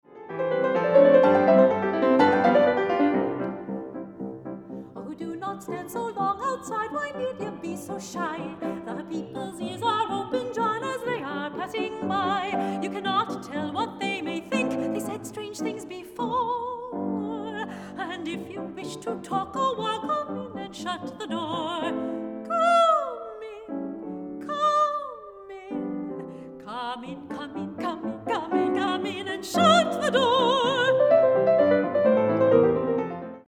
soprano
piano